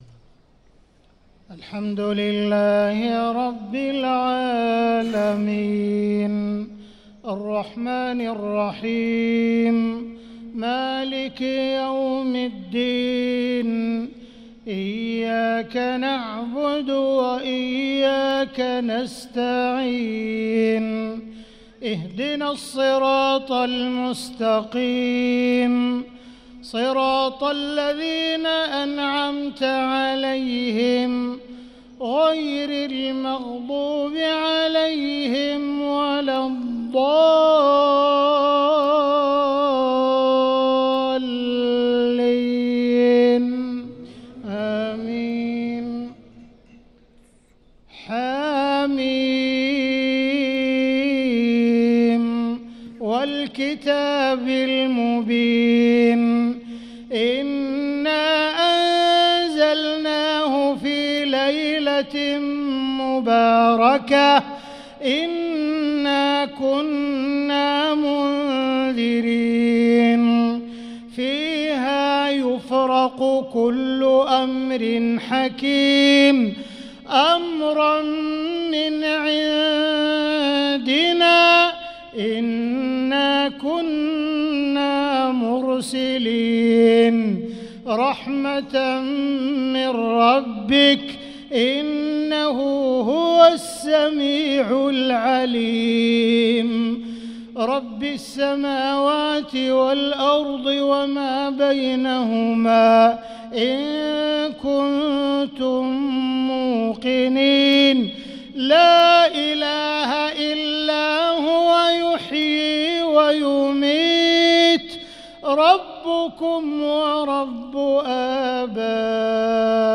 صلاة المغرب للقارئ عبدالرحمن السديس 22 رمضان 1445 هـ